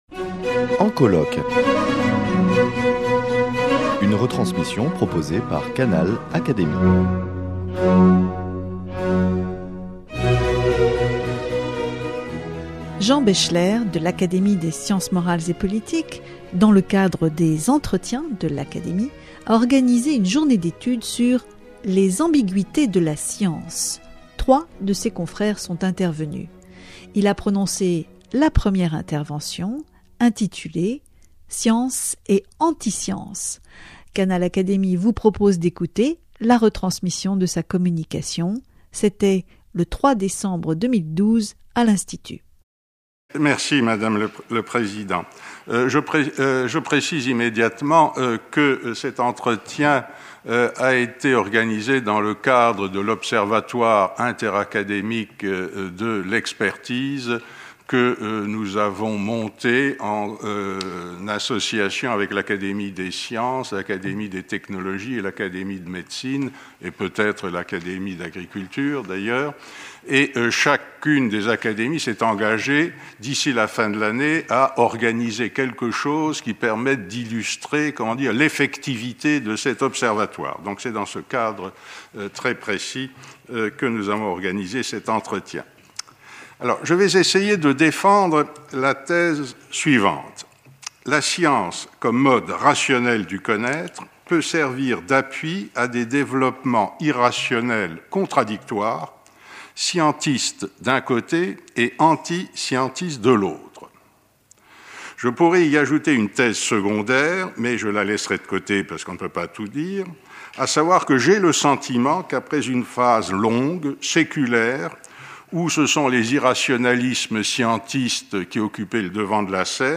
Il a prononcé la première intervention intitulée « Science et antiscience ».Canal Académie vous propose d’écouter la retransmission de sa communication.C’était le 3 décembre 2012 l’Institut.